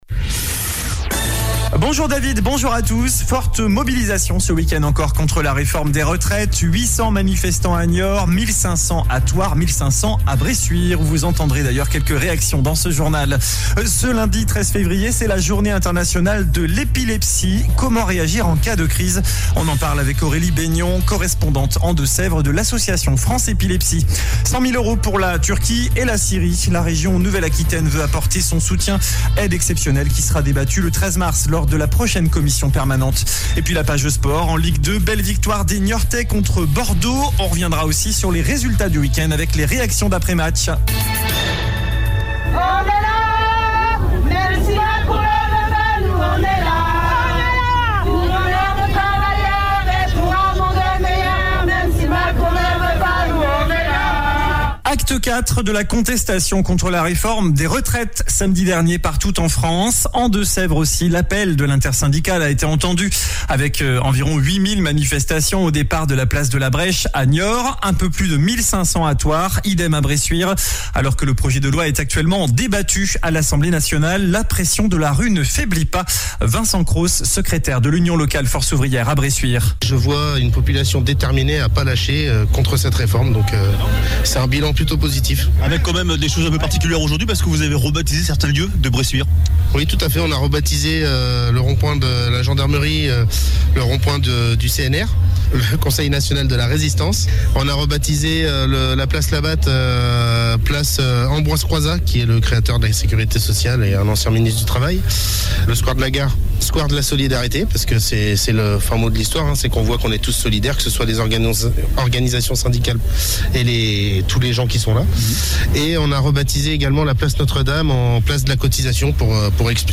JOURNAL DU LUNDI 13 FEVRIER ( MIDI )